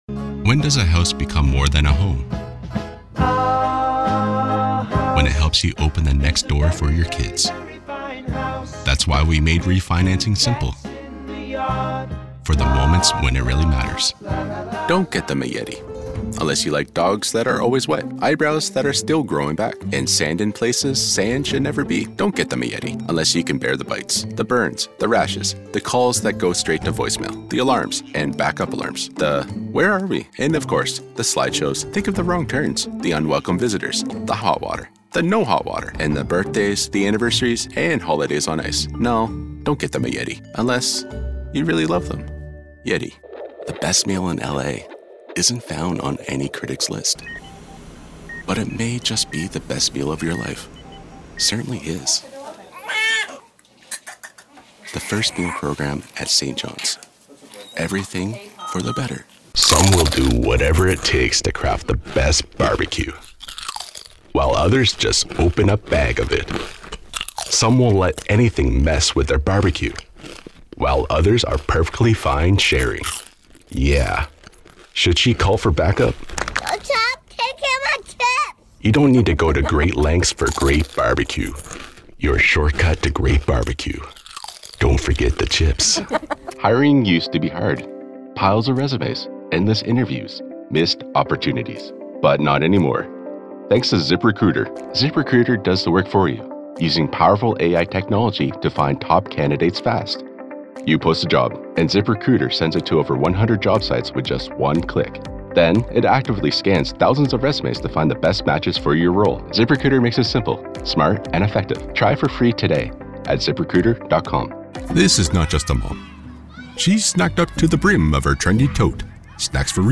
Professional COMMERCIAL Demo Reel
General Canadian, American, Native American (Indigenous), New England, Transatlantic
Sennheiser MKH 416